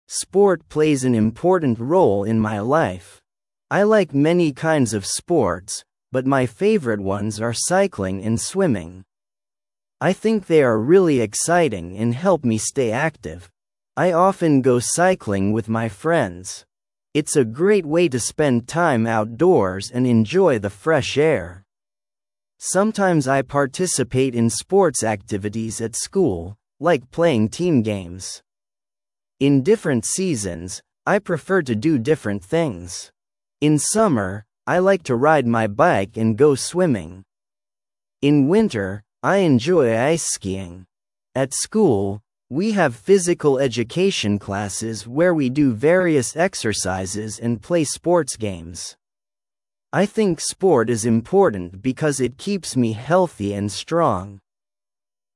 Произношение: